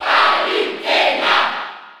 Category:Crowd cheers (SSB4) You cannot overwrite this file.
Palutena_Cheer_French_NTSC_SSB4.ogg.mp3